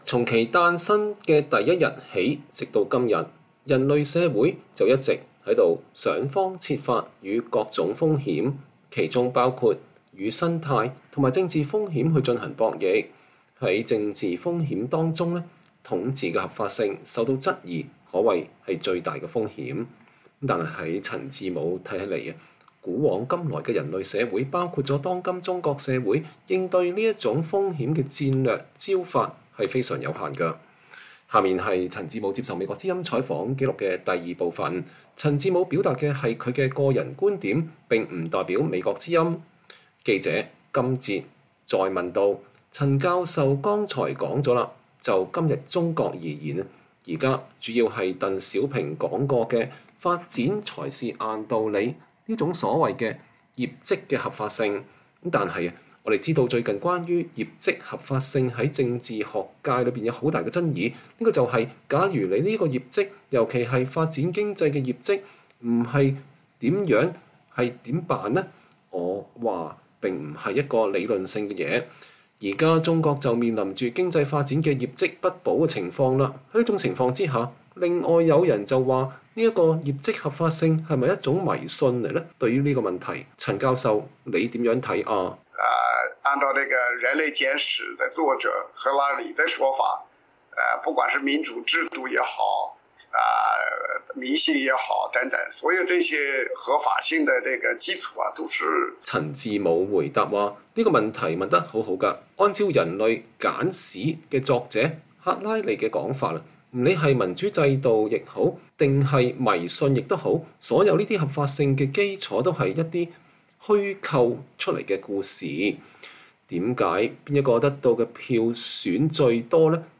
專訪陳志武(2): 談清代中國與今日中國